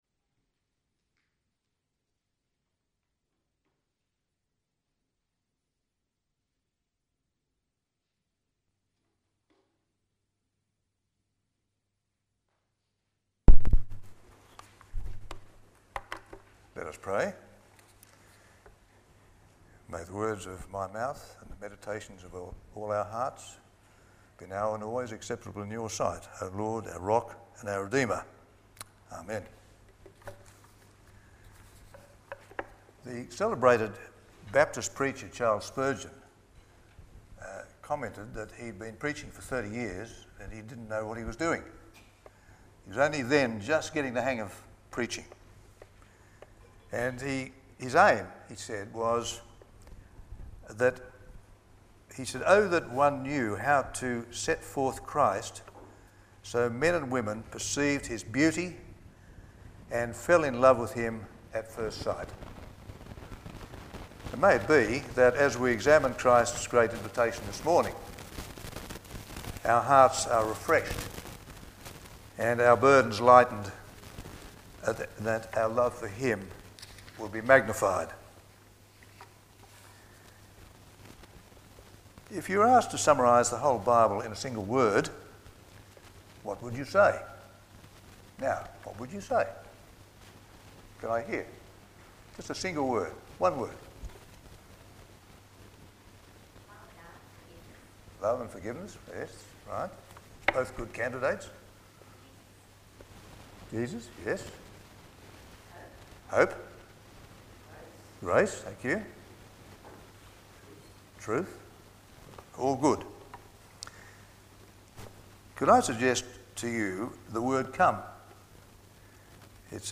A Single Sermon Passage: Isaiah 55:1-13, Matthew 11:20-30 Service Type: Sunday Morning « Has Evil Escaped God’s Attention?